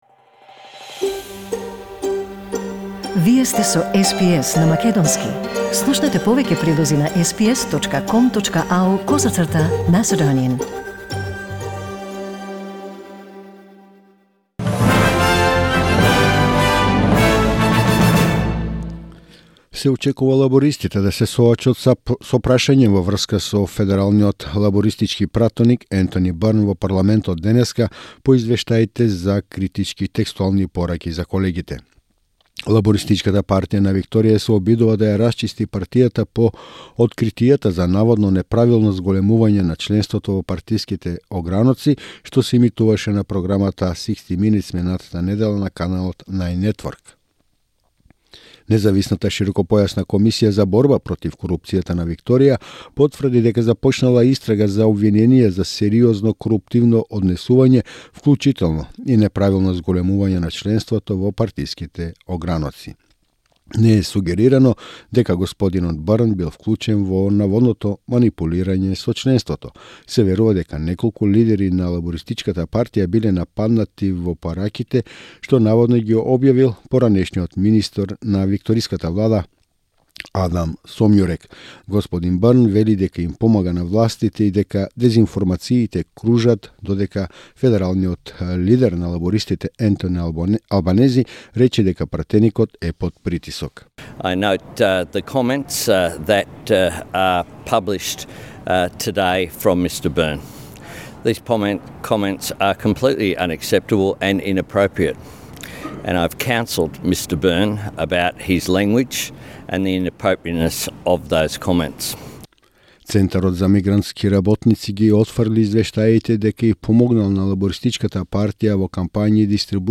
SBS News in Macedonian 18 June 2020